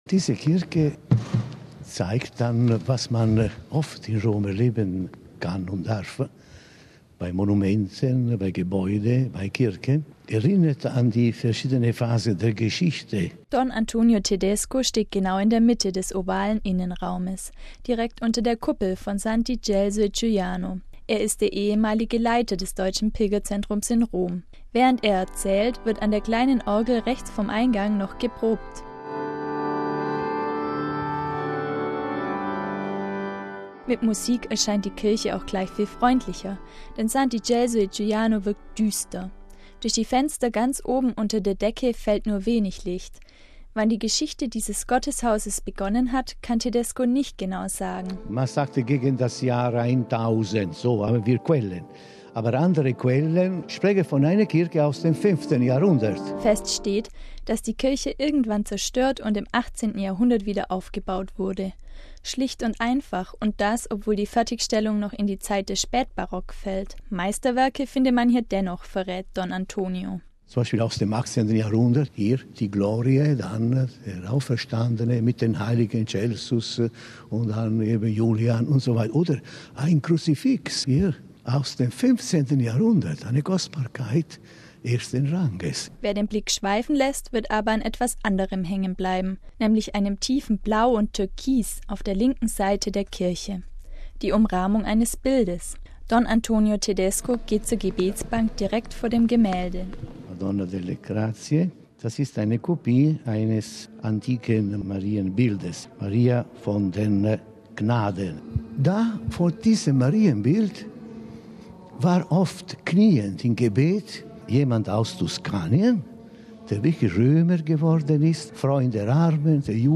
Mit Musik erscheint die Kirche auch gleich viel freundlicher.